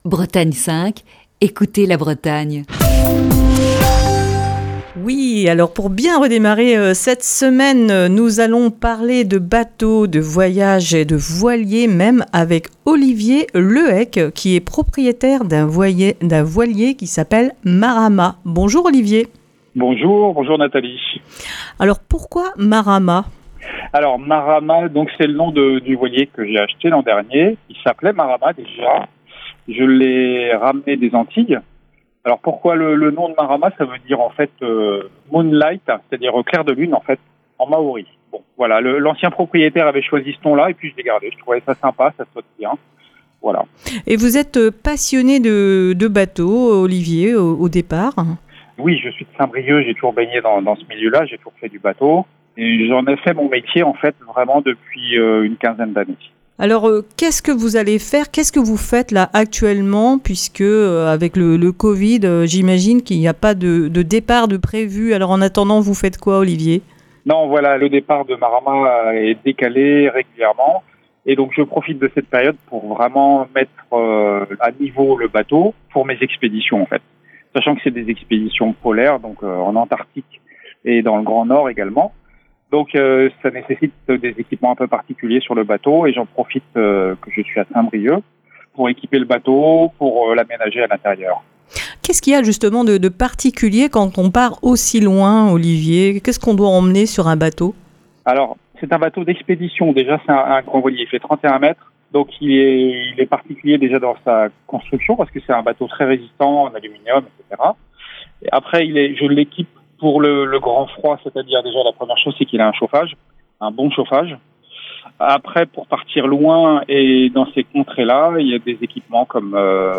au téléphone